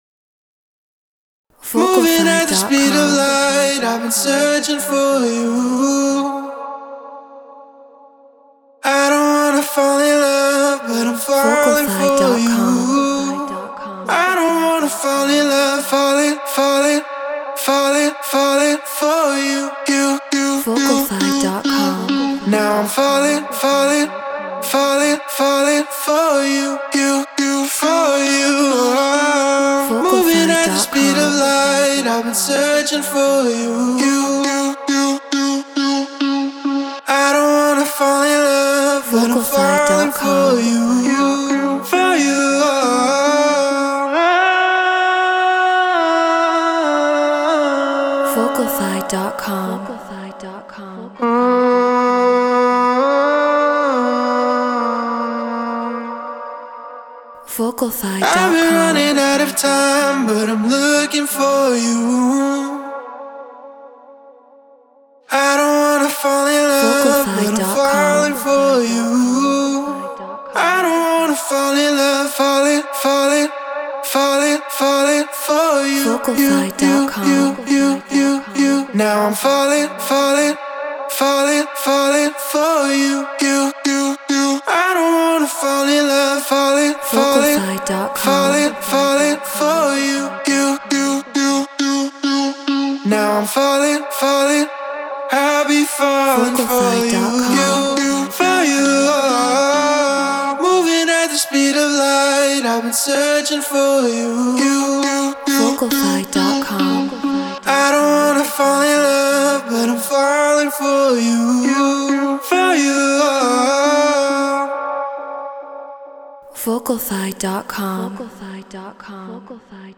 UKG 136 BPM Bmin
RØDE NT1 Focusrite Scarlett Solo FL Studio Treated Room